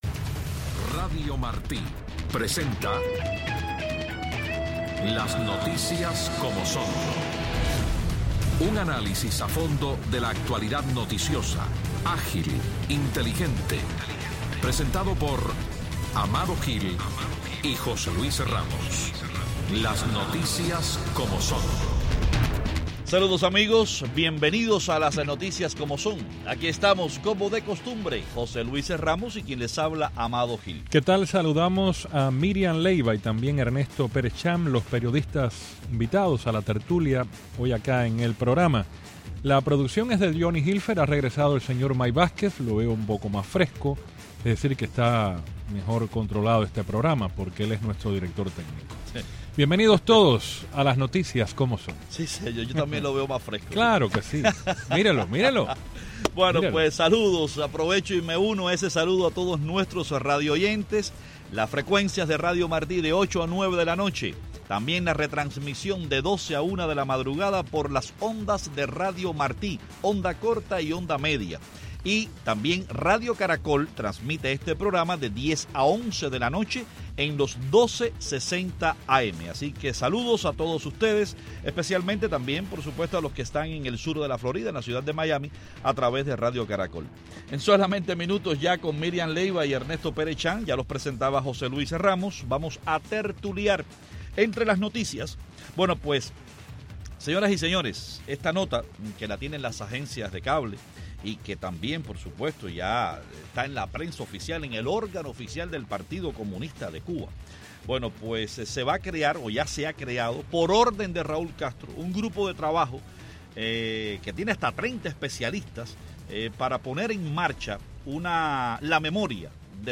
En Tertulia
ambos desde La Habana